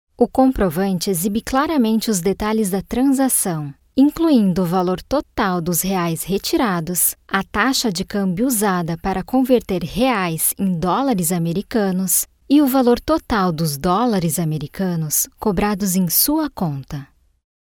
locutora brasil, Brazilian voice ver